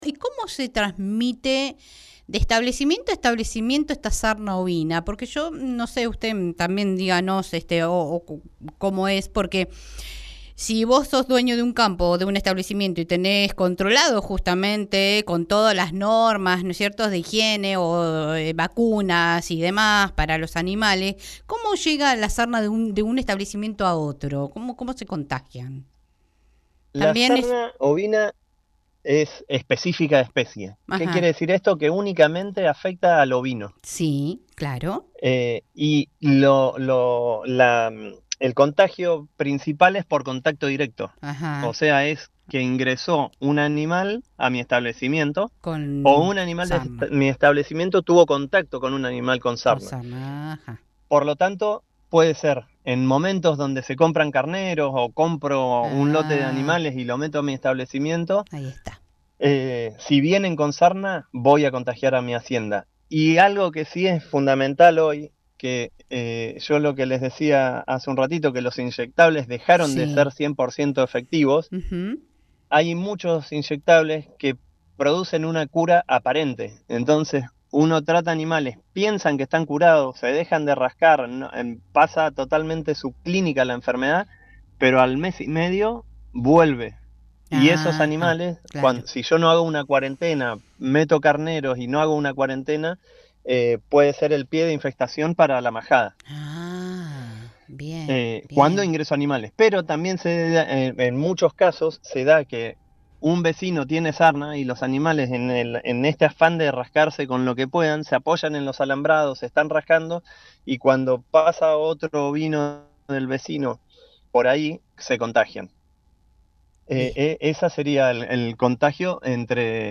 Entrevistas en Radio y Televisión.